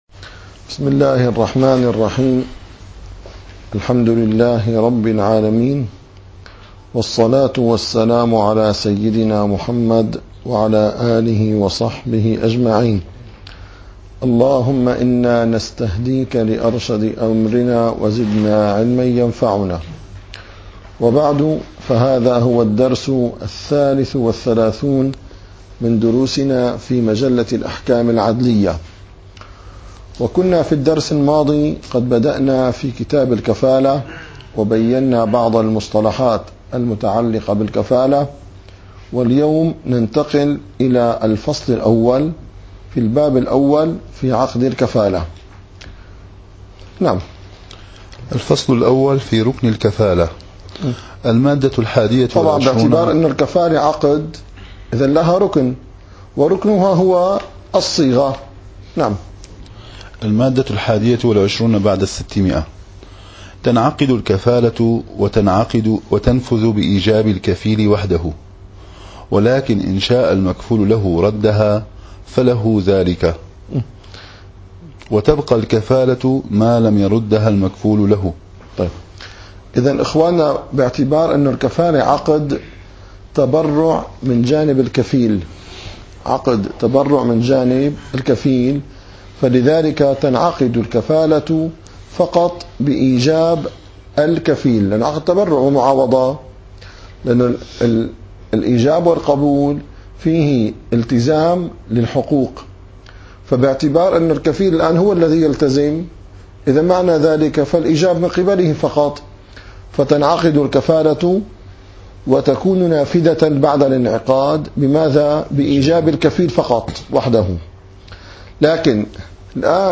- الدروس العلمية - الفقه الحنفي - مجلة الأحكام العادلية - 33- مادة 621 تنعقد الكفالة وتنفذ بإيجاب الكفيل